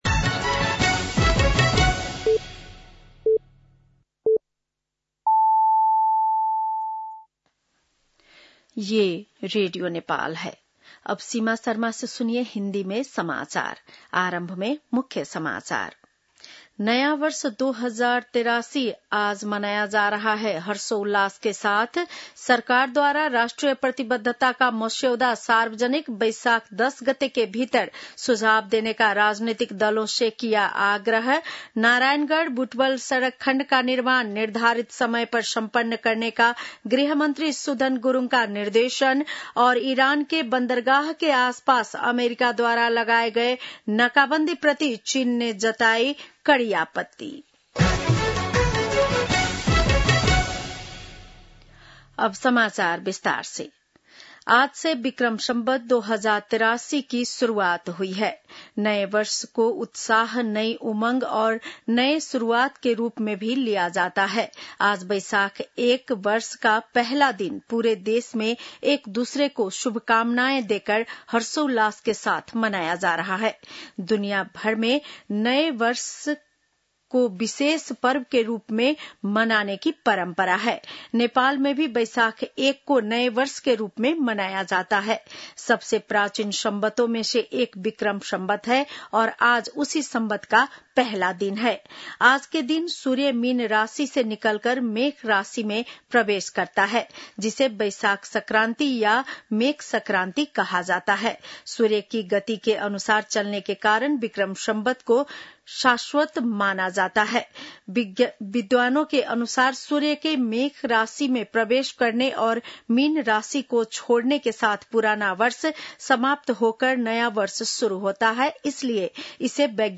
बेलुकी १० बजेको हिन्दी समाचार : १ वैशाख , २०८३
10-pm-hindi-news-1-01.mp3